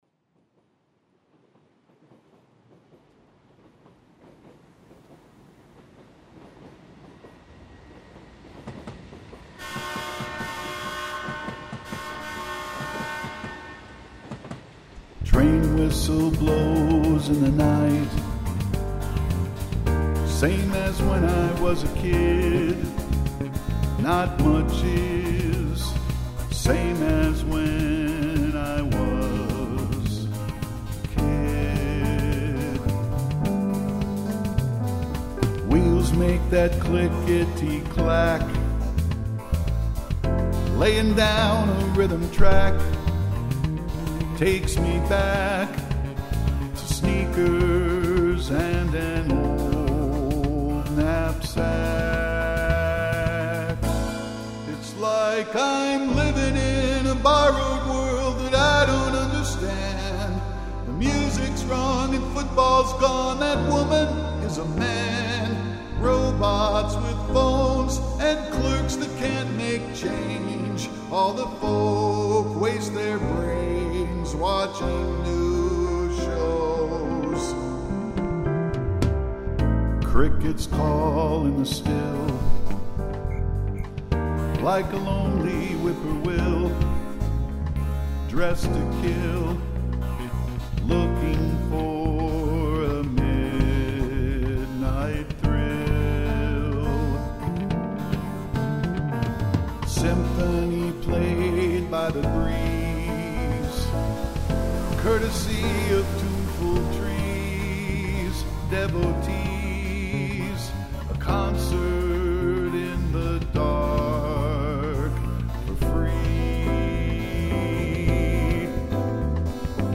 Trumpet
Bass